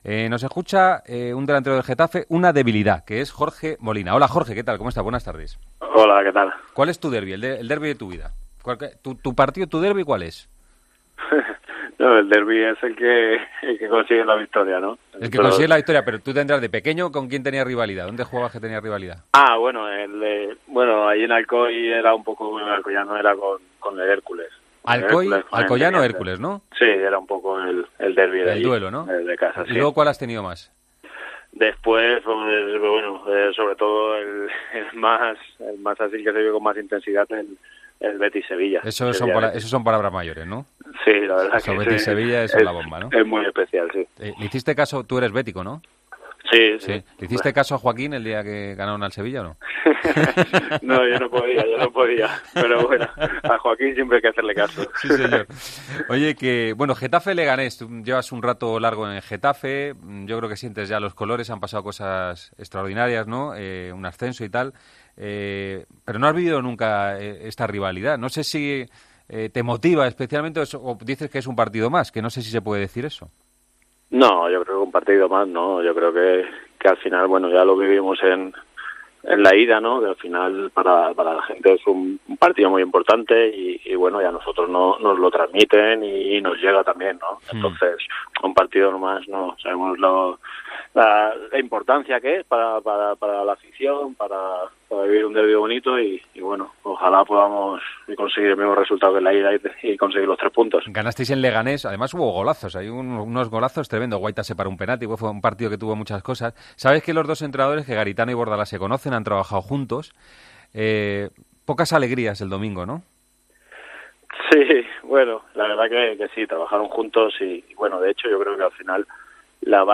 Hablamos con el capitán azulón antes del derbi de este domingo entre Getafe y Leganés. Jorge Molina cuenta que saben que no es un "partido más" y apunta que el rival "es un equipo muy trabajado y muy difícil de batir".